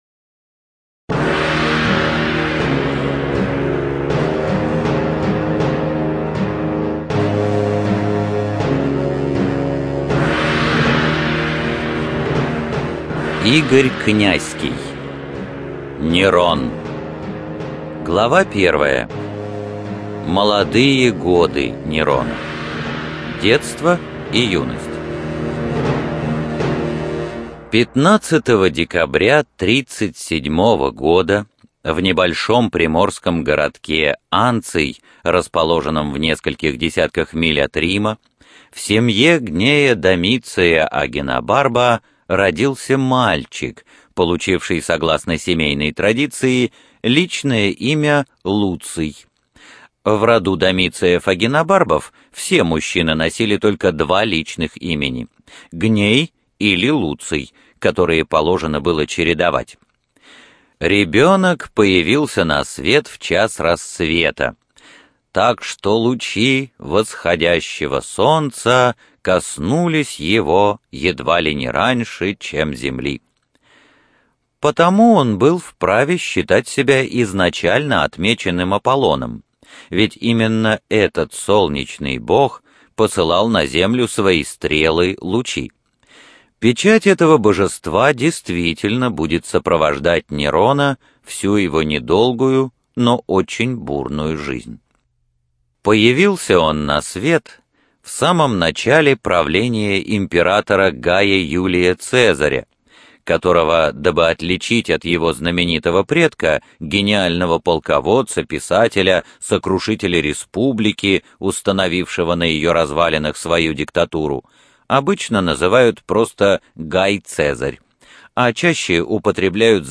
Студия звукозаписиСидиком